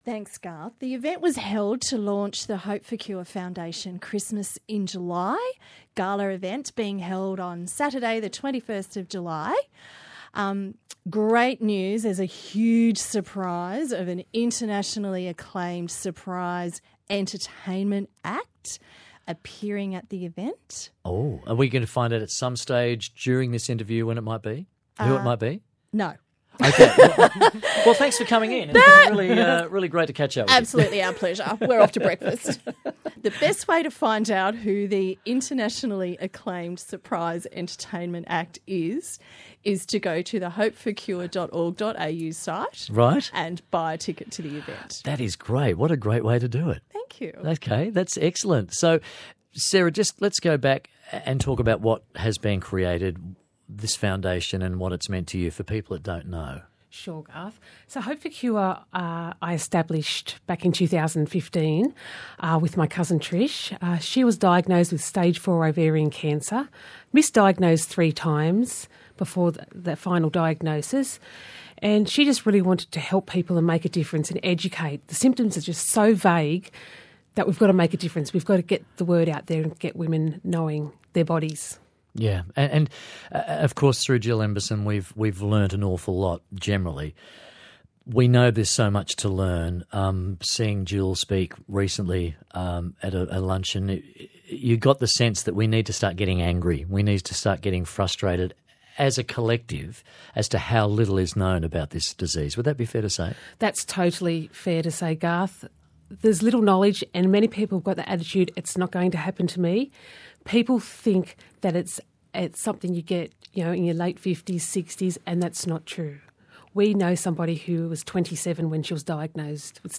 10 Jul ABC Newcastle interview